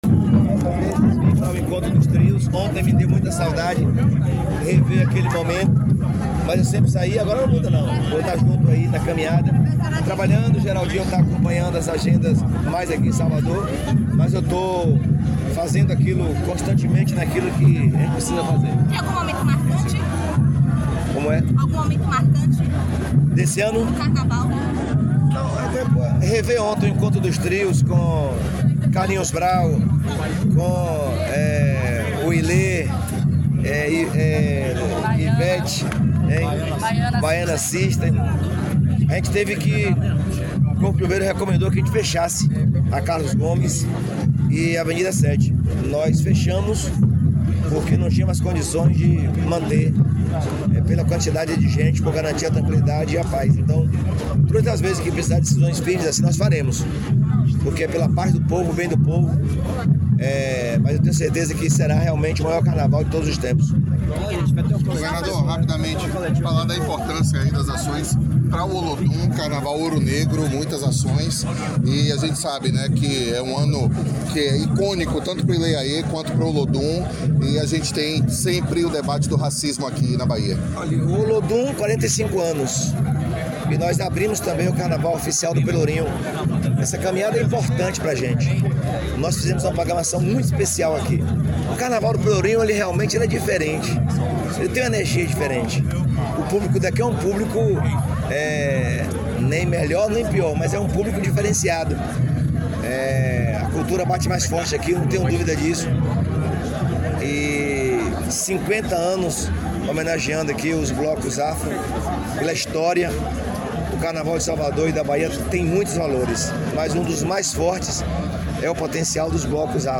🎙Governador Jerônimo Rodrigues